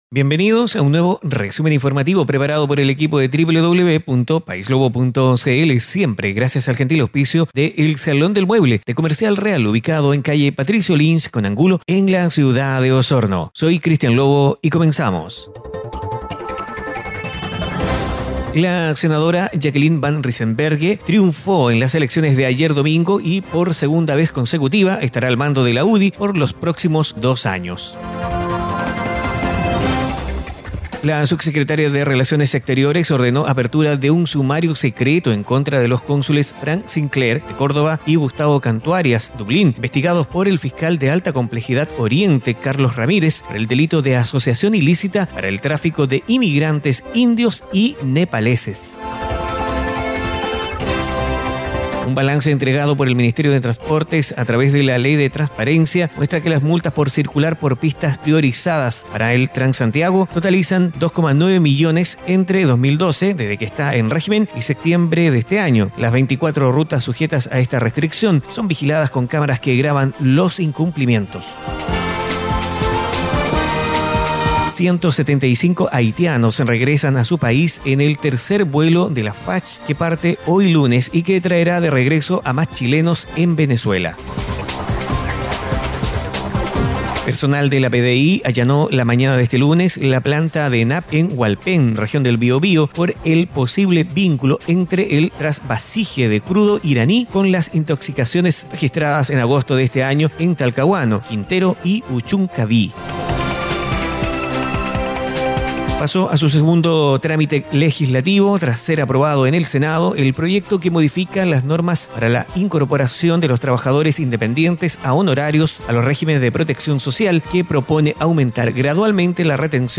Noticias en pocos minutos.